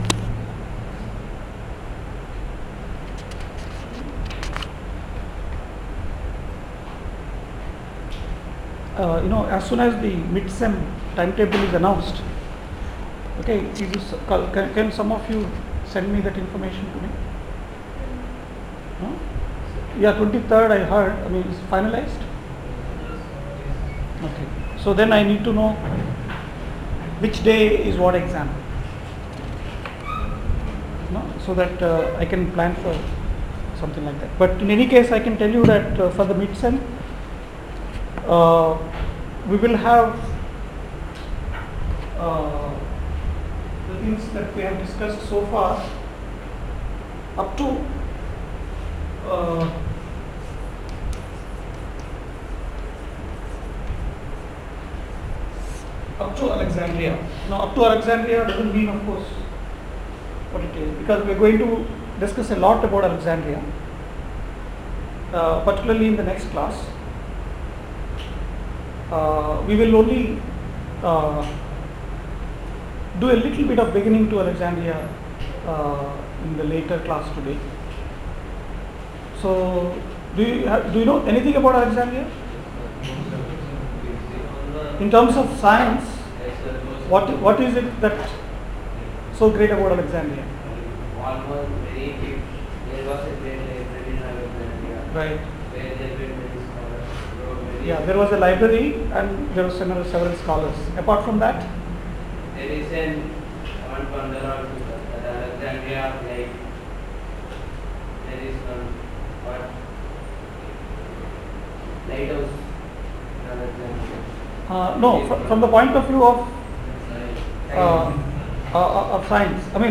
lecture 6